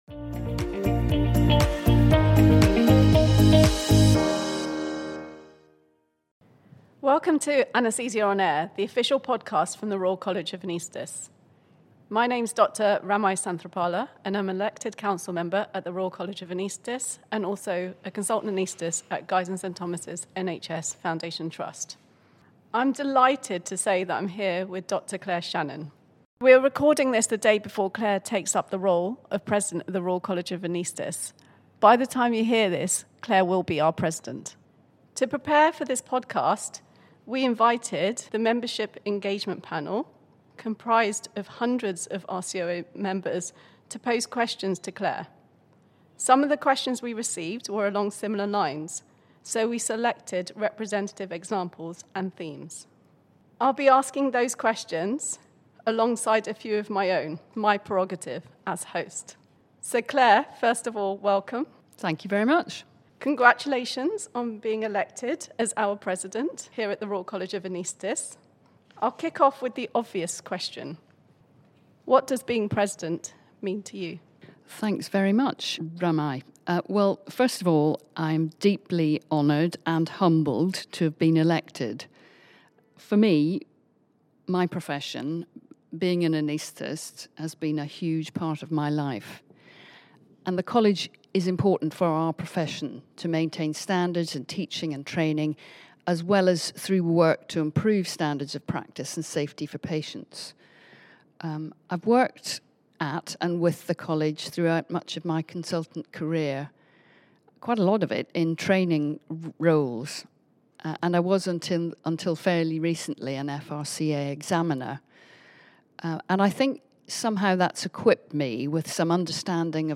This audio interview